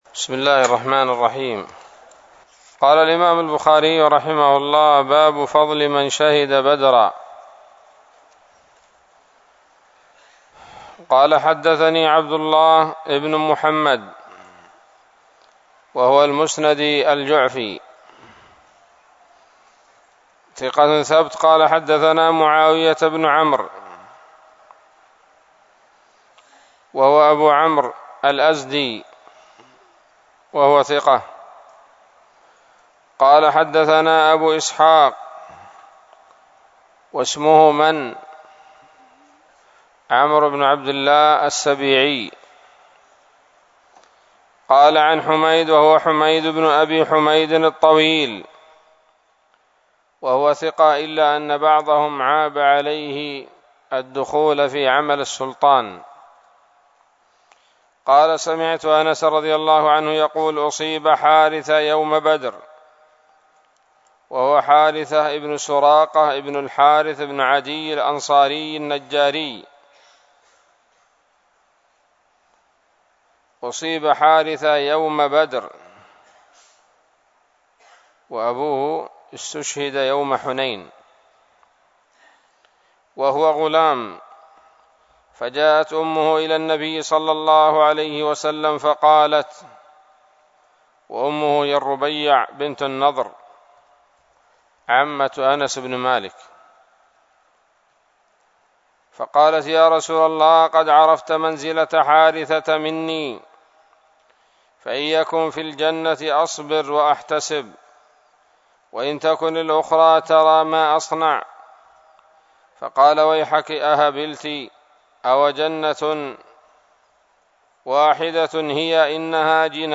الدرس الثاني عشر من كتاب المغازي من صحيح الإمام البخاري